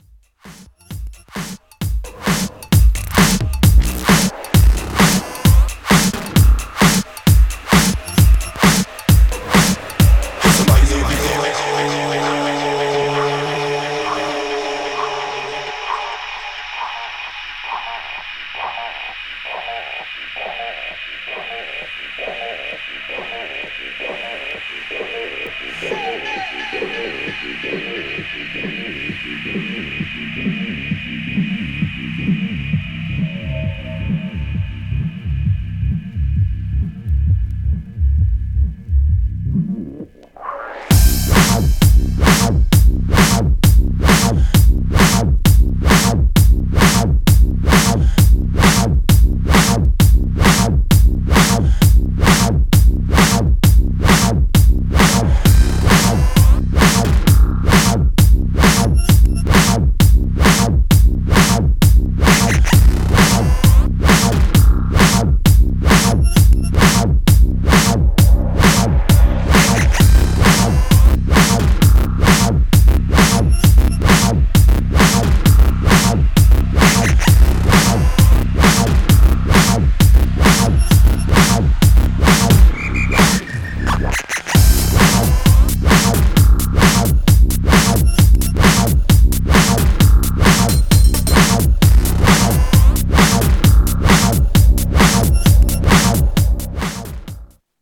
Styl: House, Breaks/Breakbeat